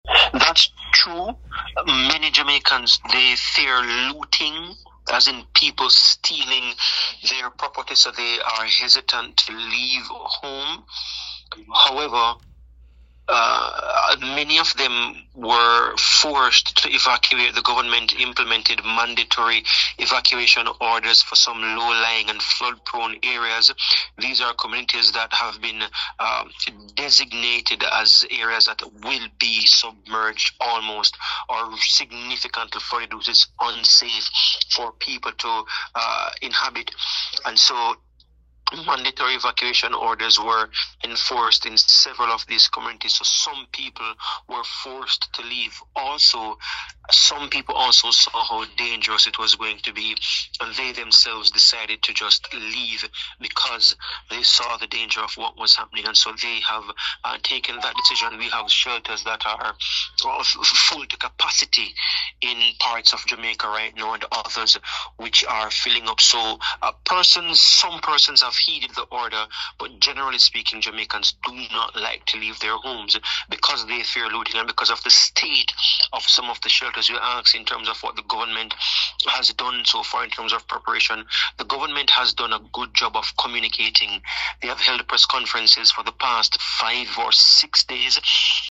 Esto es solo el principio“, aseguró mediante llamada telefónica.